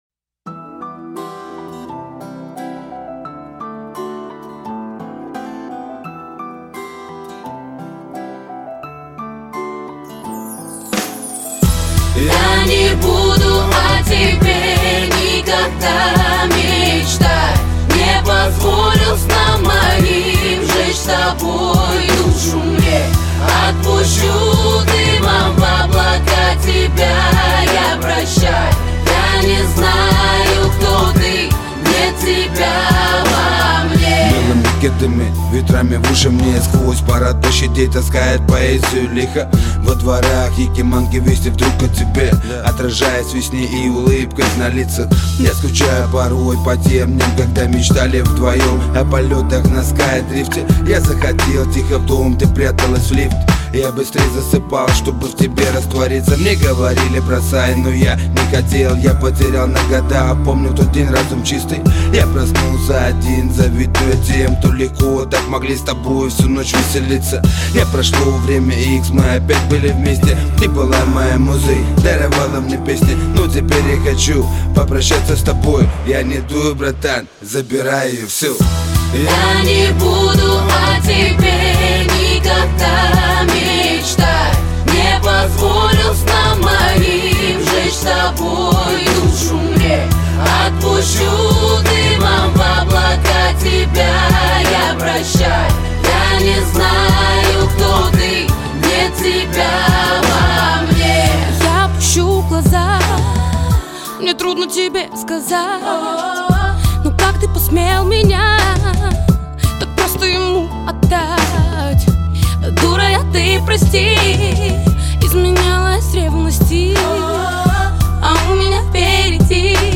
Категория: RAP, R&B